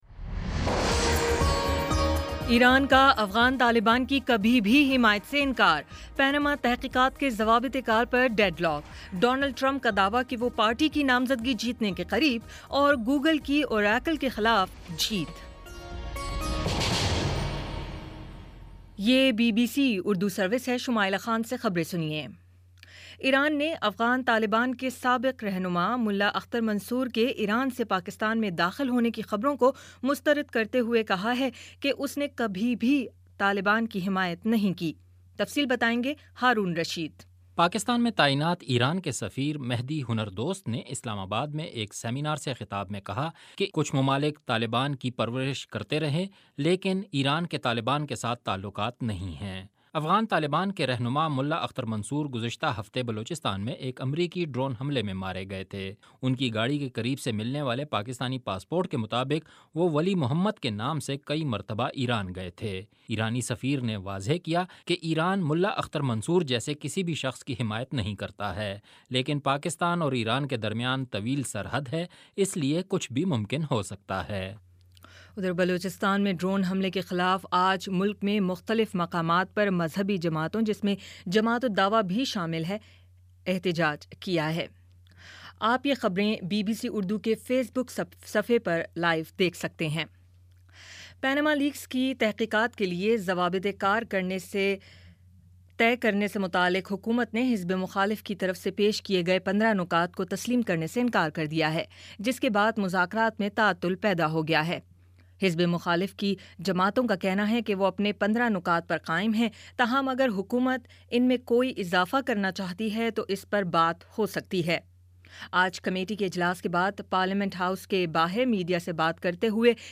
مئی 27 : شام پانچ بجے کا نیوز بُلیٹن